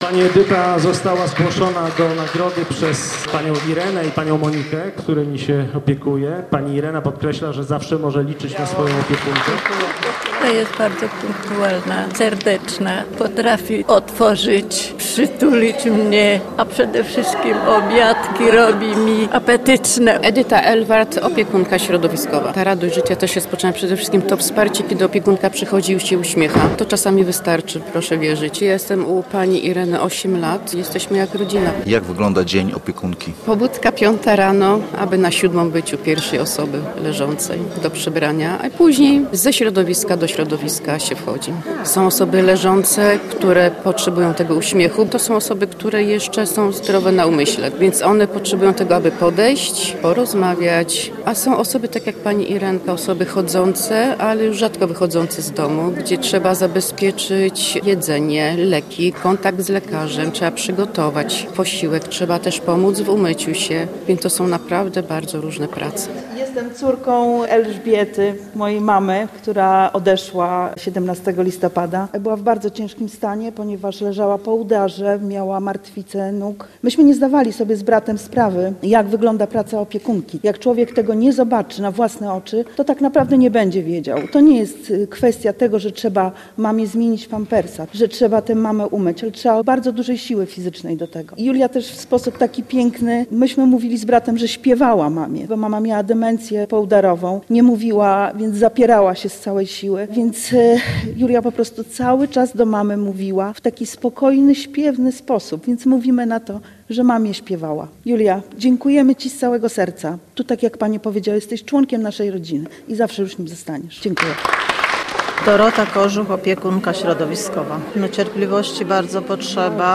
Uroczystość wręczenia dyplomów i statuetek nagrodzonym opiekunkom środowiskowym odbyła się w Sali Herbowej Urzędu Miasta Sopotu.